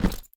sfx_检阅道具.wav